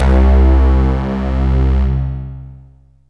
SYNTH LEADS-1 0002.wav